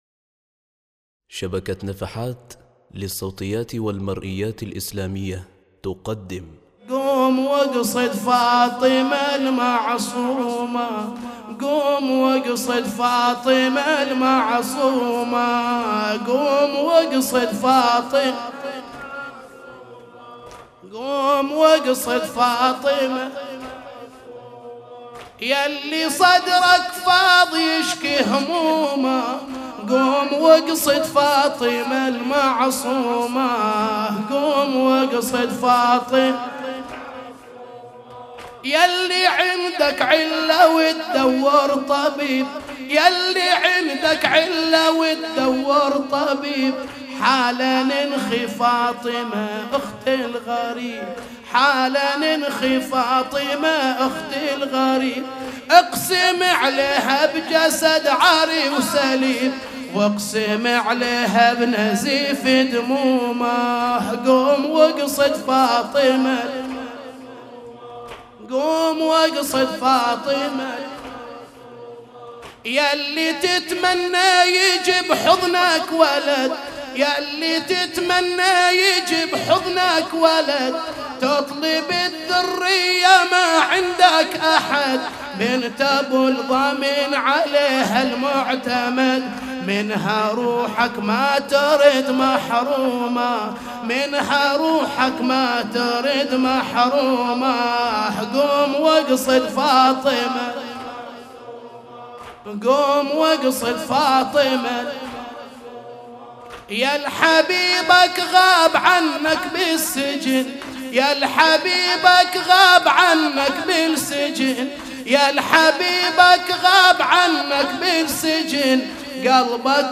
لطمية قوم وأقصد فاطمة المعصومة